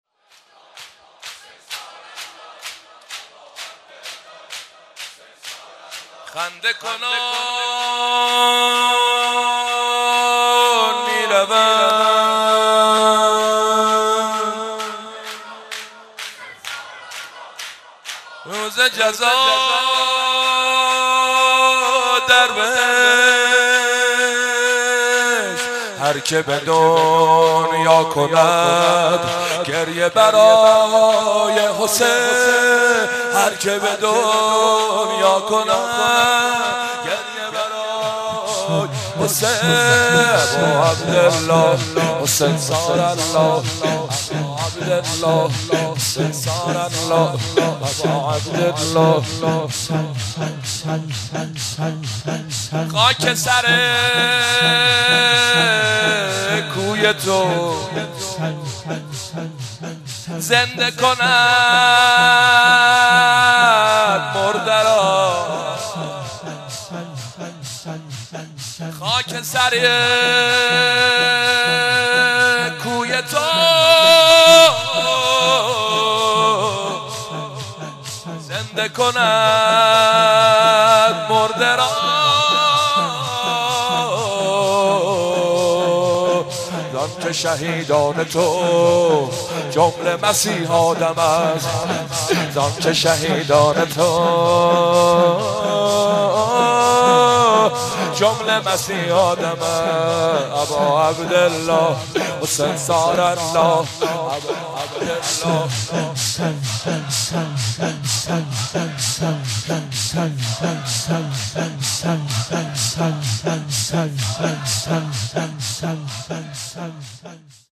مداح
قالب : زمینه